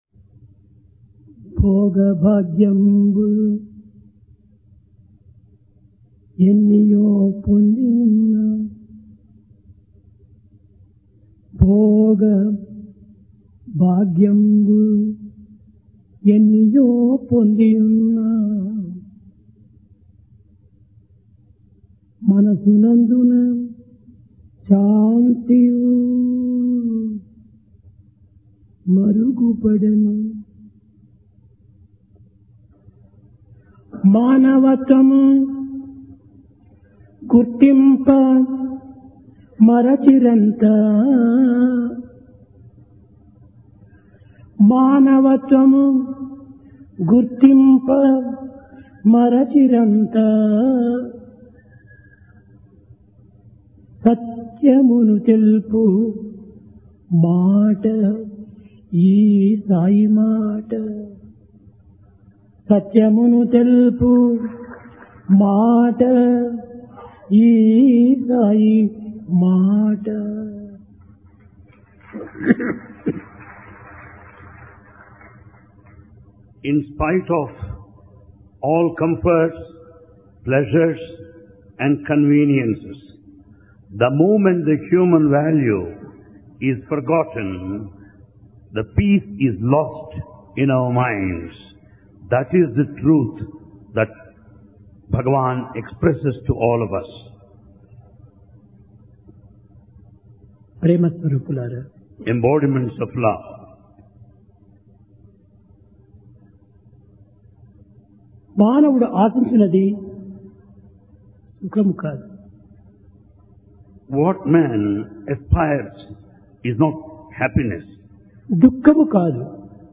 Divine Discourse, July 23, 2002, International Seva Conference Valedictory Ceremony